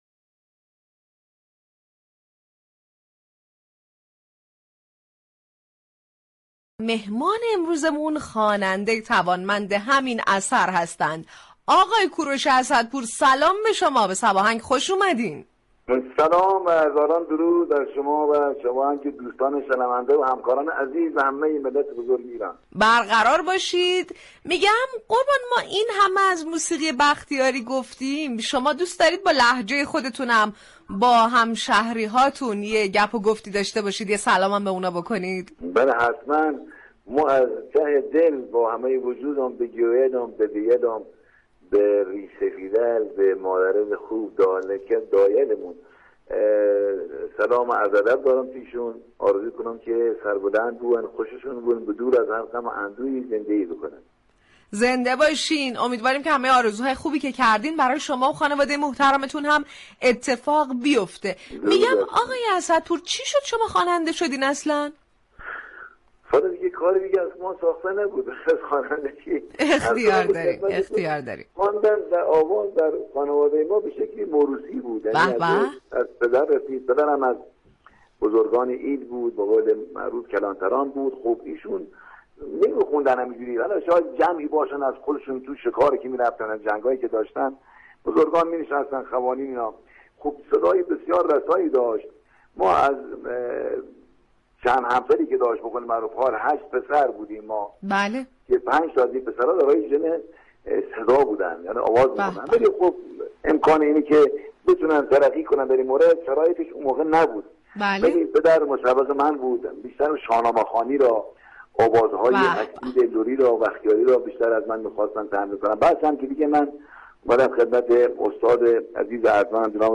به گزارش روابط عمومی رادیو صبا، برنامه موسیقی محور« صباهنگ» در بخش مهمان ویژه هر روز میزبان یكی از هنرمندان عرصه موسیقی می شود.